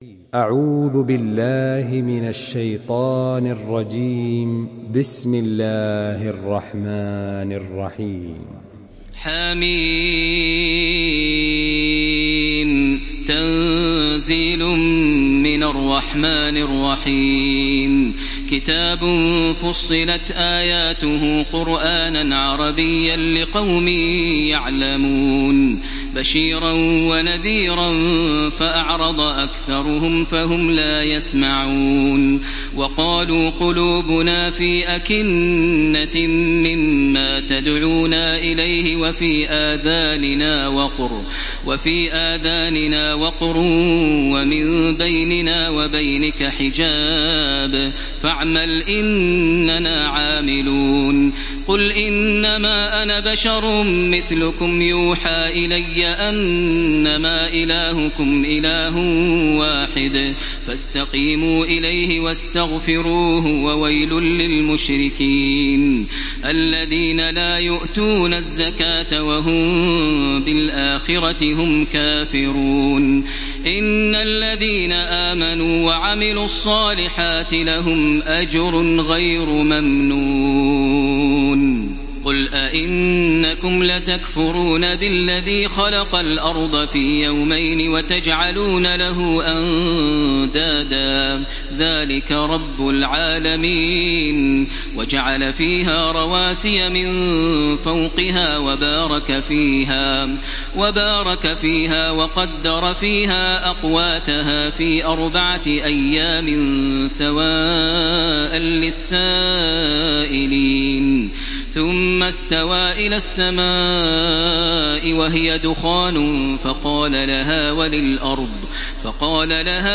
Surah Fussilat MP3 by Makkah Taraweeh 1434 in Hafs An Asim narration.
Murattal